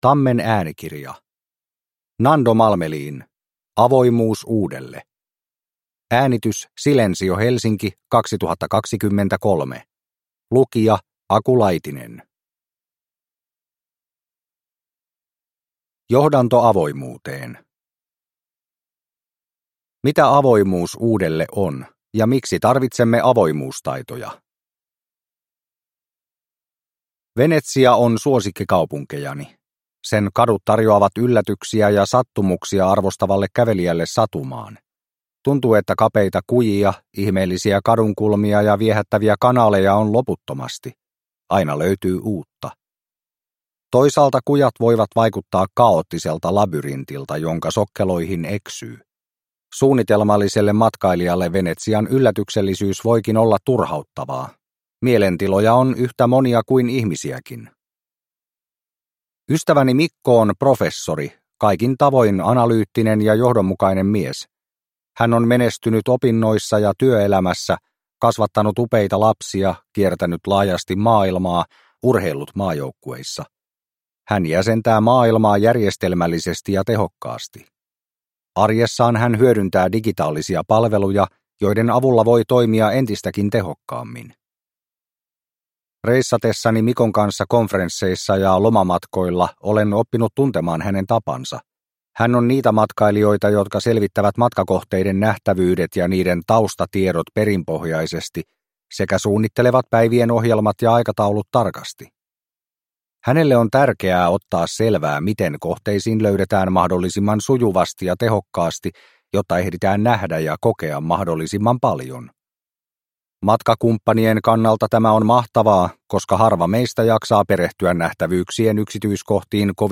Avoimuus uudelle – Ljudbok